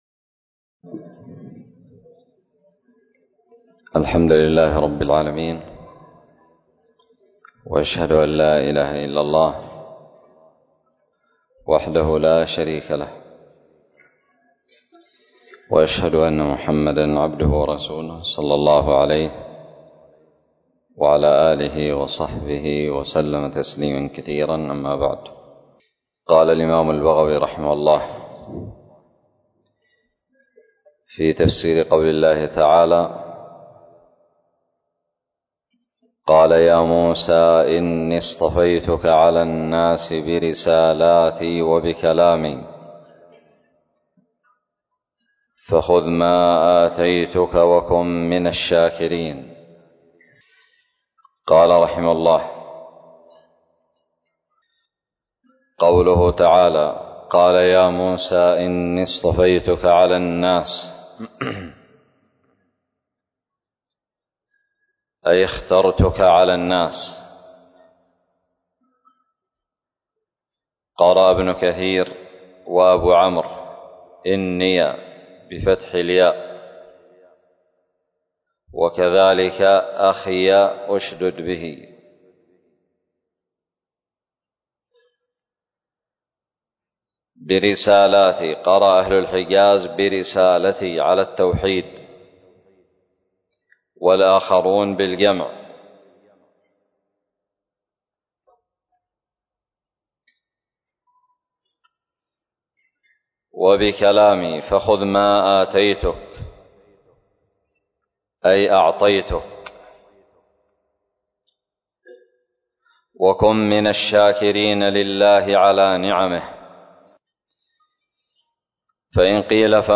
الدرس الثلاثون من تفسير سورة الأعراف من تفسير البغوي
ألقيت بدار الحديث السلفية للعلوم الشرعية بالضالع